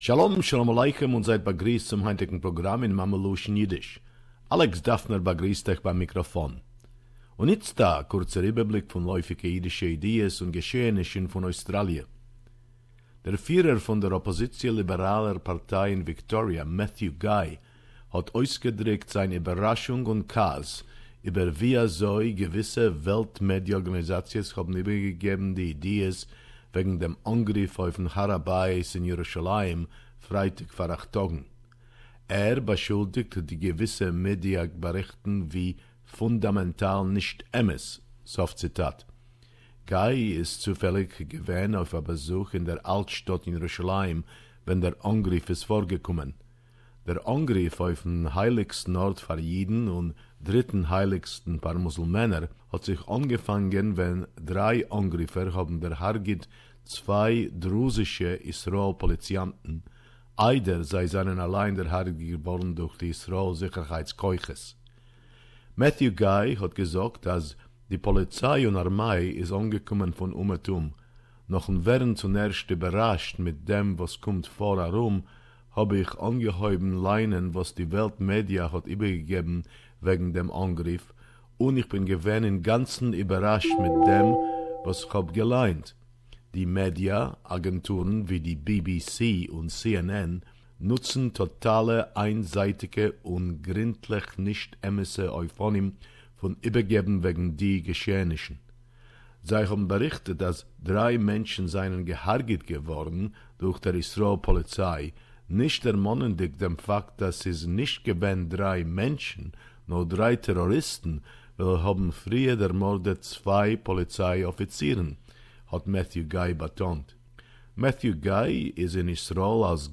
weekly report about Jewish current affairs in the Yiddish language